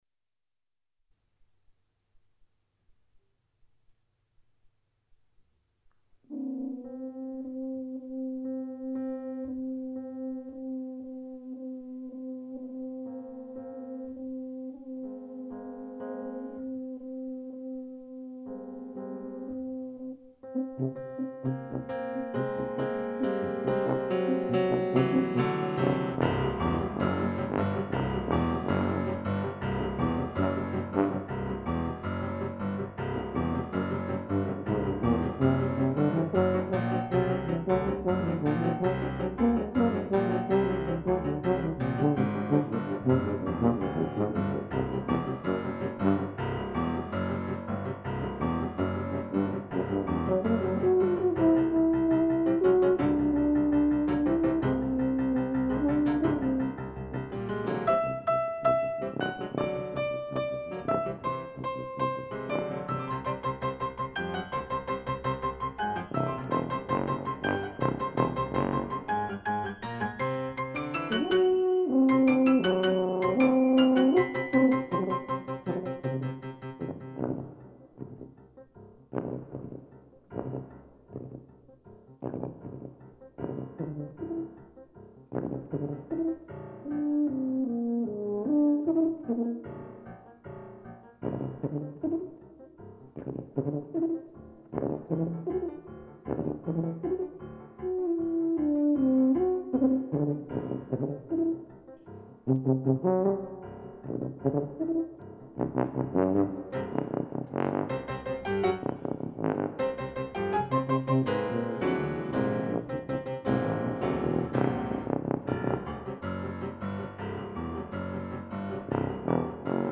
para Tuba e Piano